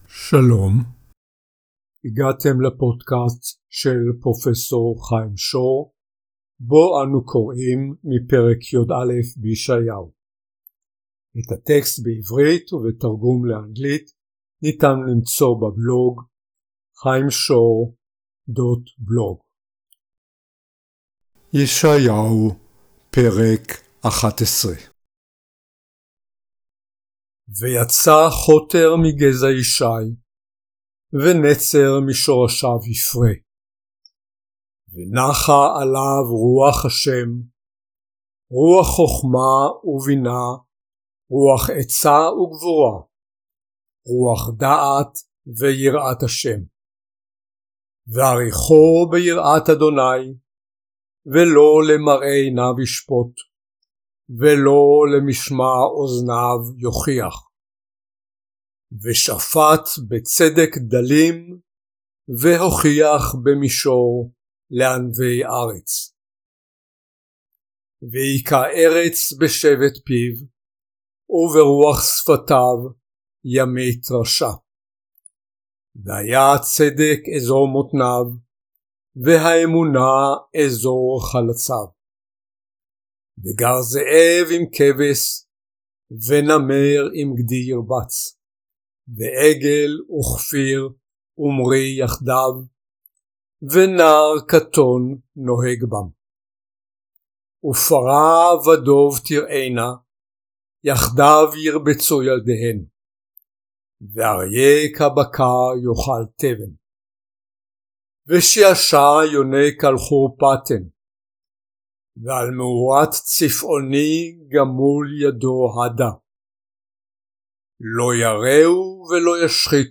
End-time scenarios, as described by prophet Isaiah, Ch. 11, read in native Hebrew (audio and a PDF file, Hebrew/English, available for download).